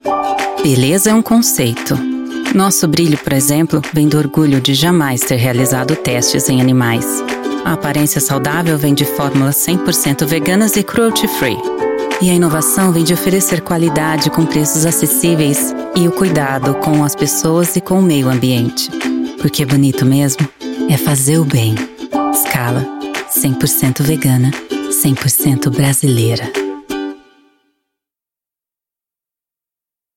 Institucional
Dê mais vida aos seus projetos com uma voz feminina moderna, profissional e cativante!